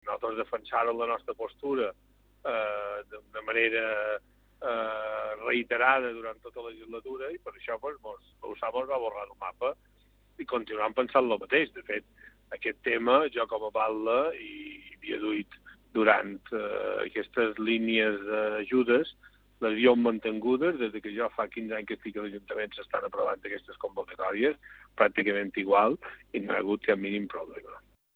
Ho ha dit a l’Informatiu Vespre d’IB3 Ràdio.